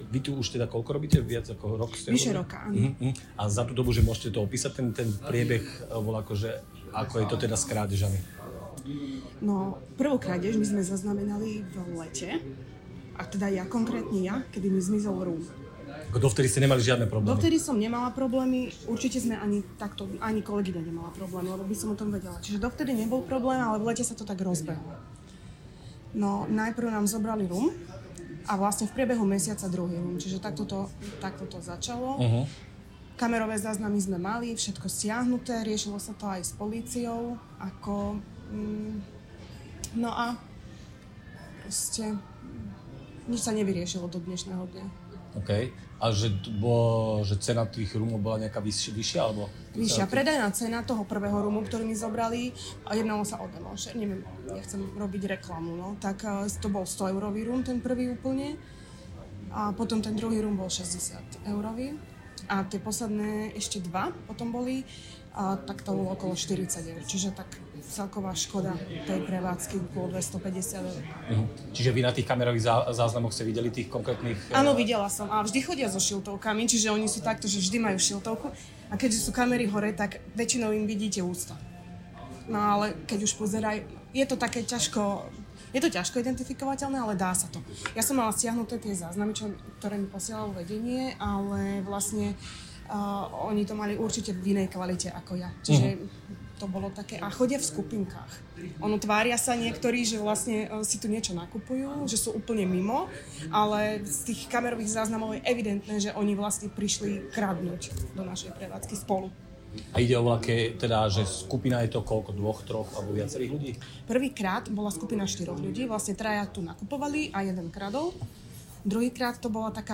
Rozhovor s predavačkou 1
Rozhovor s predavačkou o krádežiach v obchodoch.